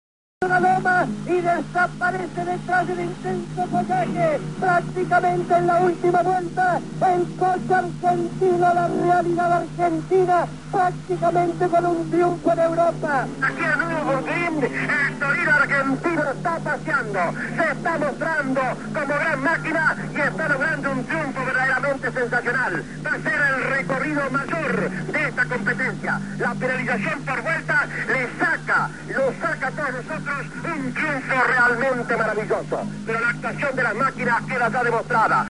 Audio de la transmisión radial de la ultima vuelta.
torino3_nurburgring.mp3